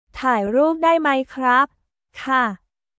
ターイ ループ ダイ マイ クラップ/カー